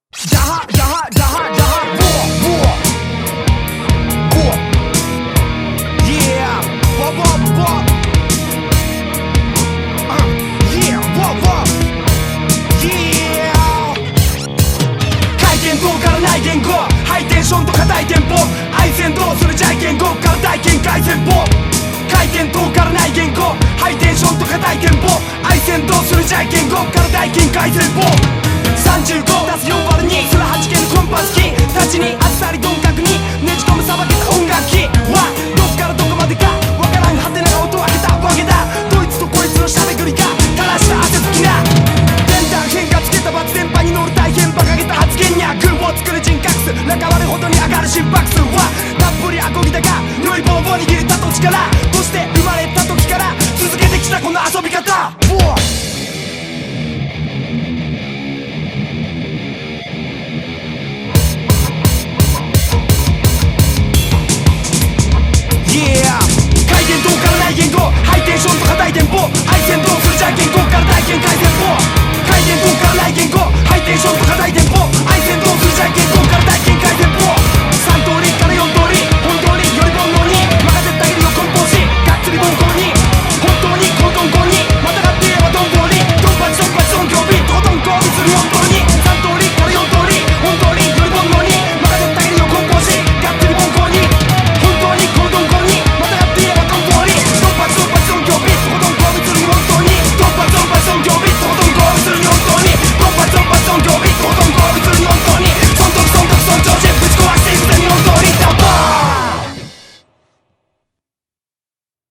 BPM143-172
Audio QualityPerfect (High Quality)
Comentarios[HIP ROCK]